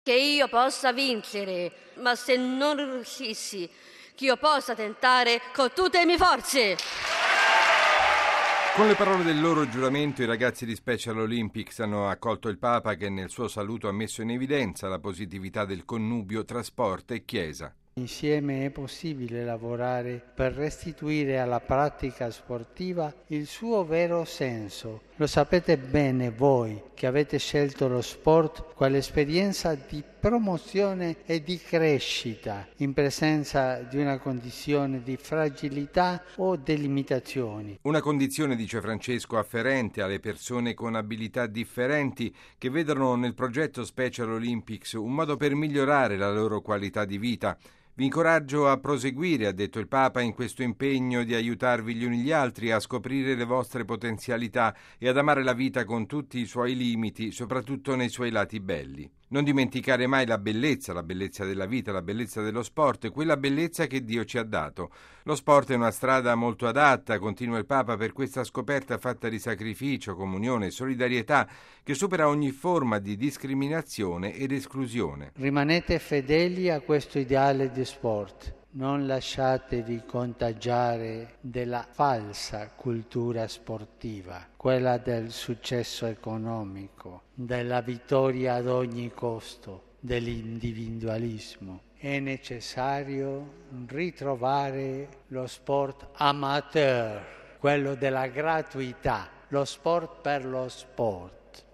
Il Pontefice li ha ricevuti a un mese dai Giochi Olimpici di Los Angeles, che si svolgeranno a fine luglio. Il servizio